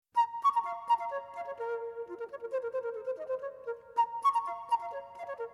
Auf der CD: Ton Koopman und das "Amsterdam Baroque Orchestra" mit Musik zur Geschichte.